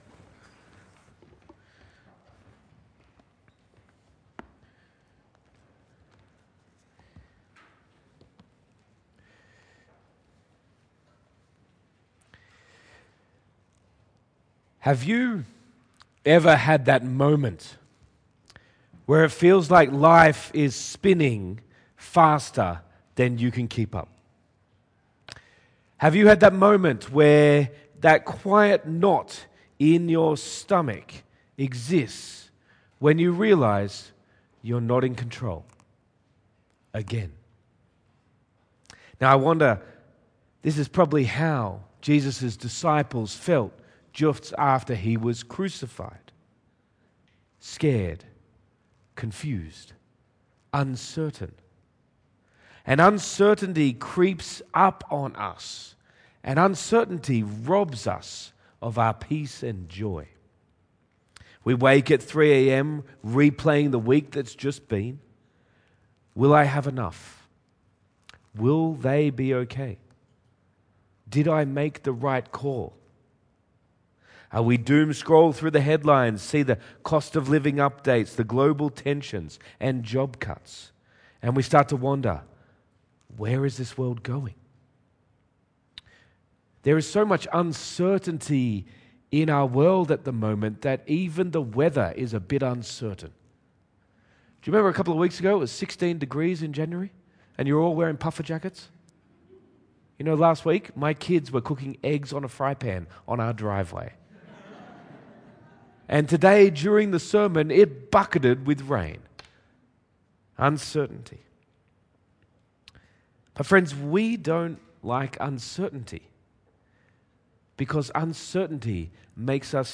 Sermon Series | St Matthew's Wanniassa